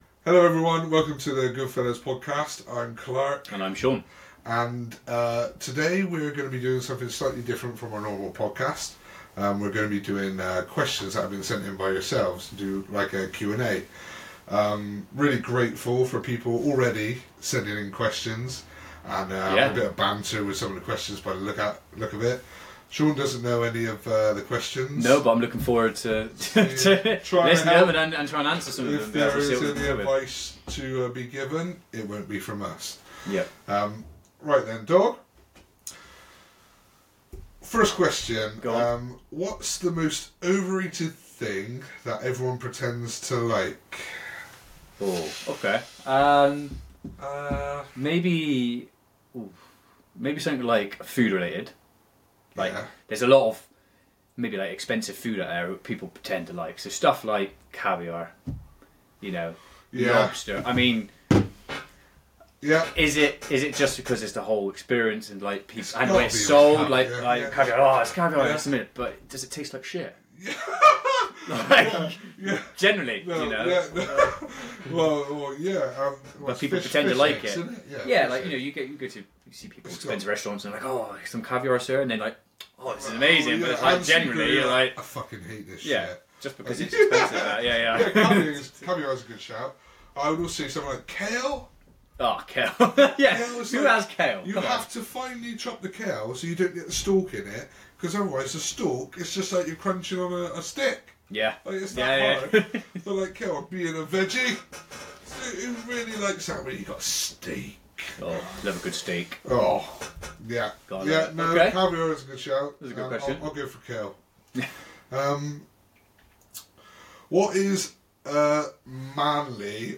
The conversation flows naturally, with humor and banter as they share personal anecdotes and insights on manly experiences, iconic films, and essential life skills. They invite listeners to send in their questions for future episodes, making it an interactive and engaging experience.